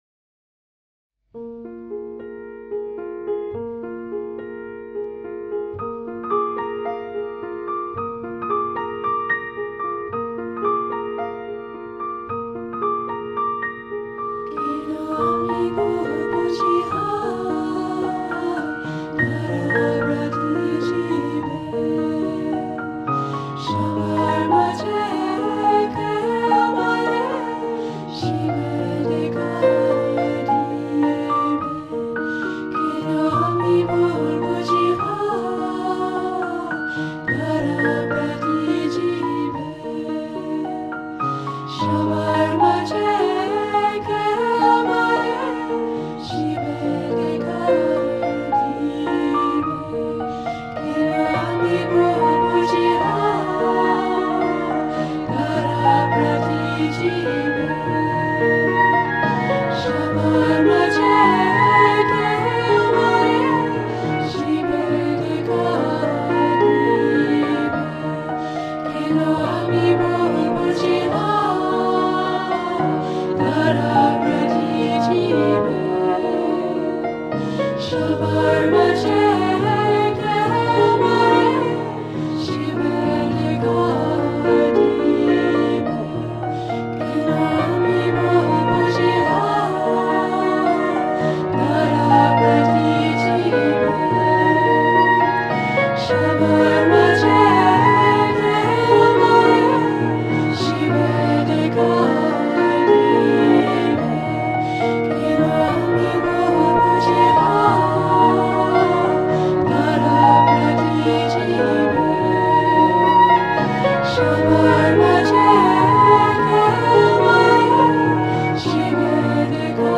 pour un son à la fois contemplatif et joyeux.